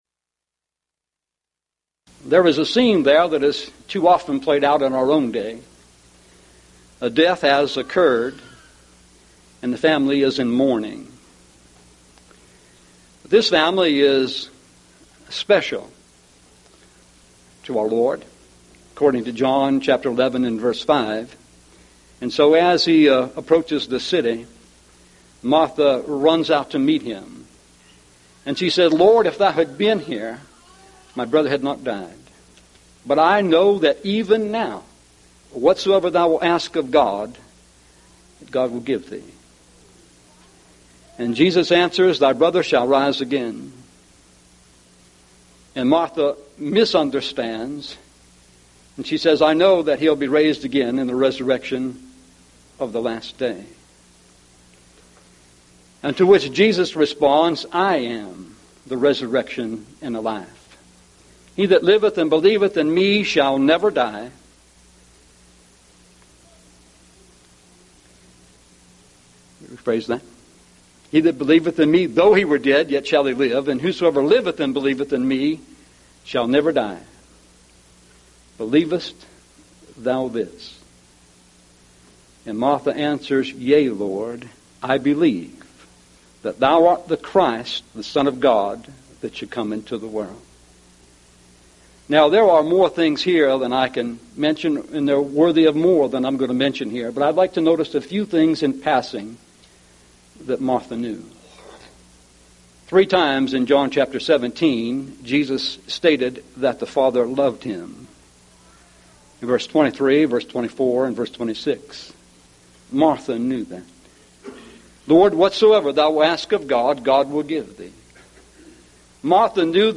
Event: 1998 Mid-West Lectures